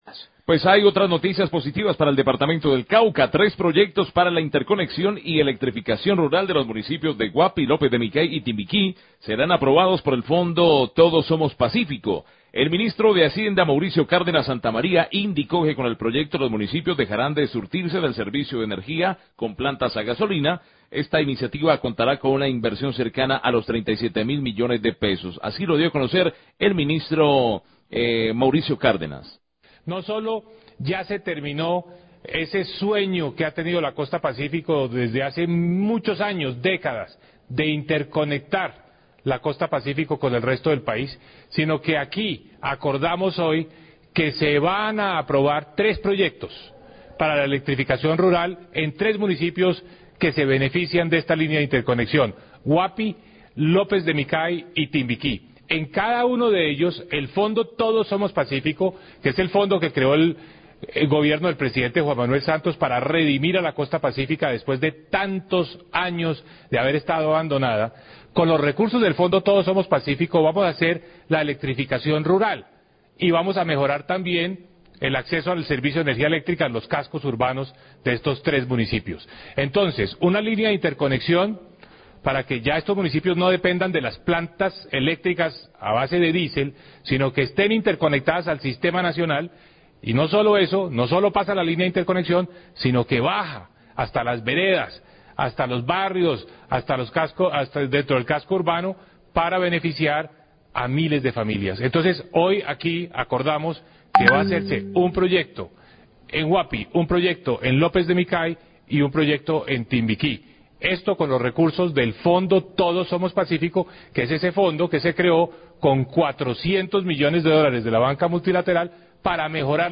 Radio
Tres proyectos de interconexión electrifica para tres municipios de la costa pacífica caucana serán aprobados por el Plan Todos Somos Pazcífico, la iniciativa va a contar con una inversión cercana a los 37 mil millones de pesos. Declaraciones de Mauricio Cárdenas, Ministro de Hacienda.